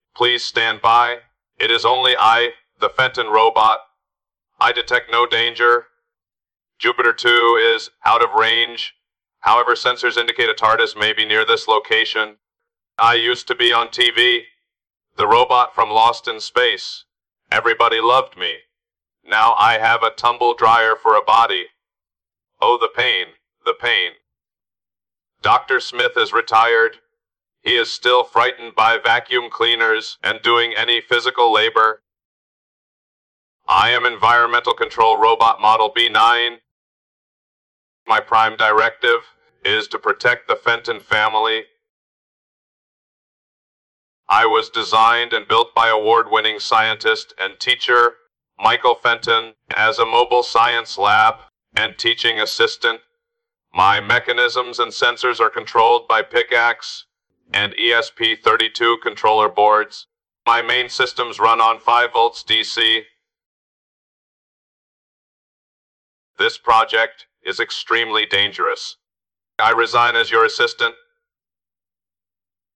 Download B9 robot personality voice file (MP3)
B9-voice-test.mp3